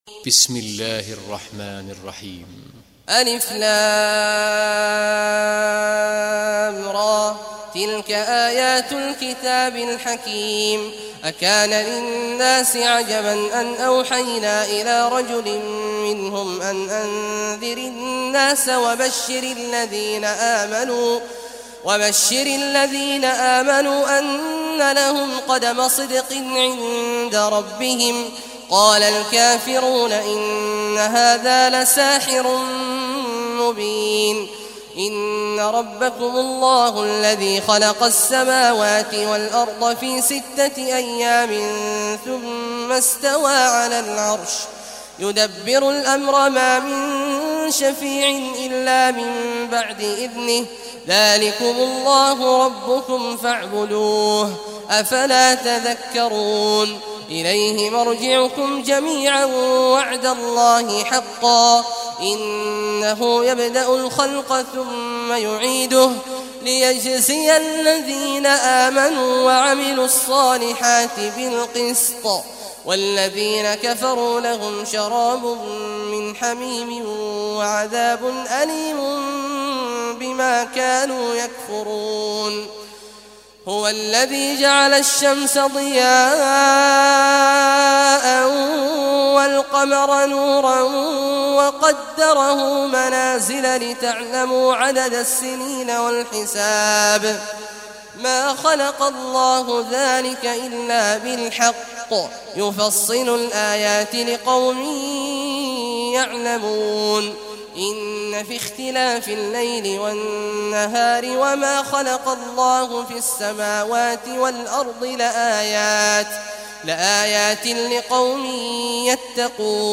Surah Yunus Recitation Sheikh Abdullah Awad Juhany
Surah Yunus, listen online mp3 tilawat / recitation in Arabic in the beautiful voice of Sheikh Abdullah Awad al Juhany.